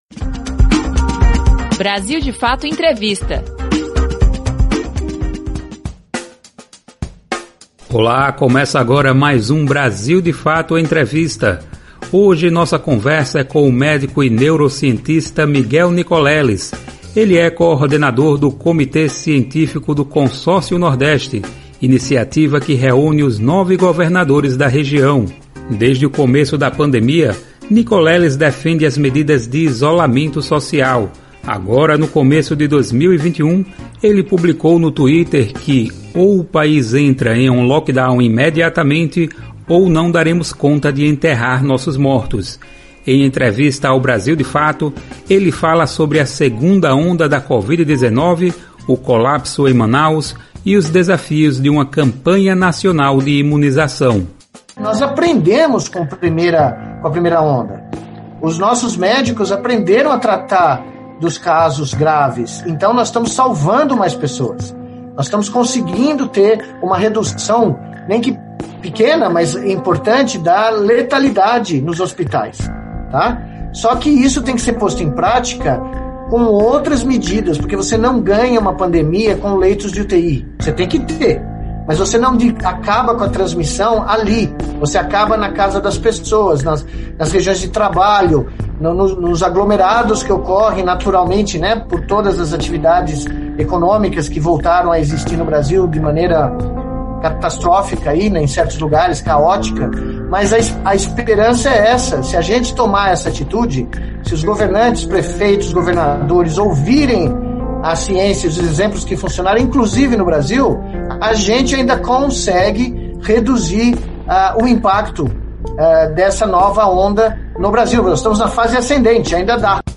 BdF Entrevista